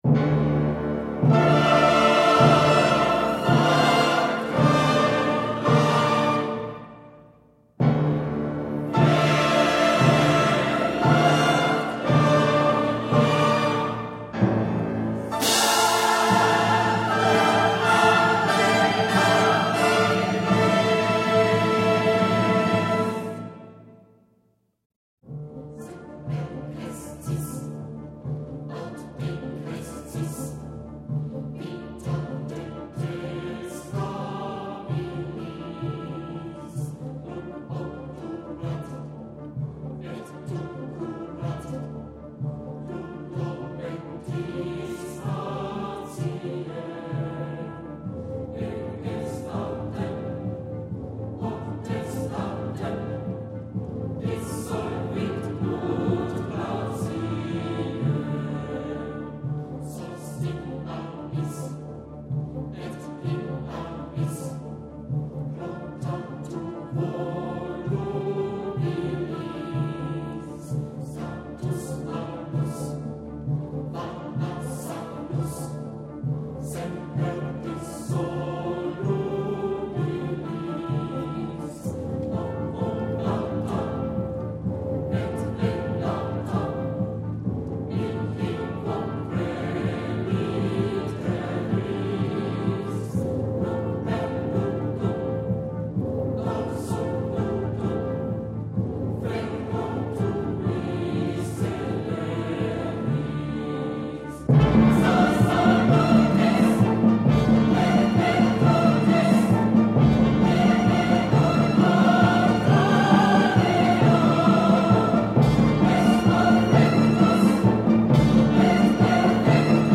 تقدیم به طرفداران موسیقی کلاسیک